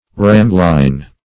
Search Result for " ramline" : The Collaborative International Dictionary of English v.0.48: Ramline \Ram"line\ (r[a^]m"l[i^]n), n. A line used to get a straight middle line, as on a spar, or from stem to stern in building a vessel.